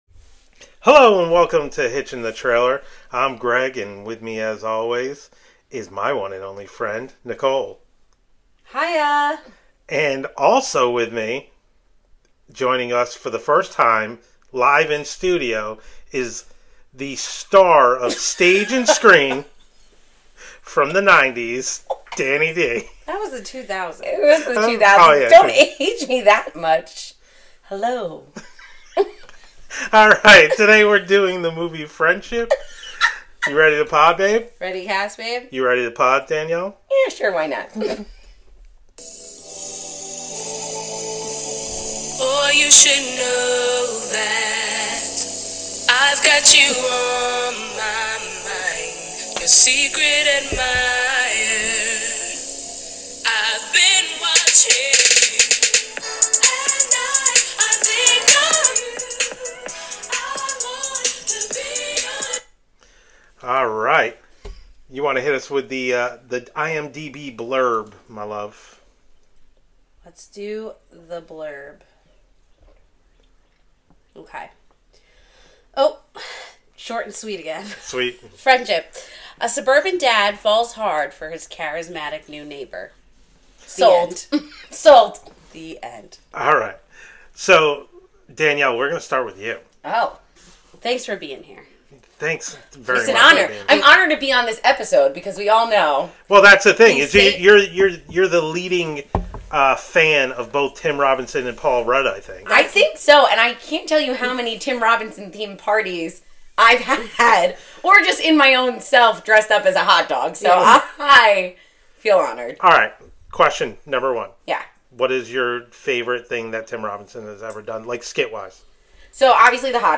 Just a couple who love watching trailers, talking about everything and making each other laugh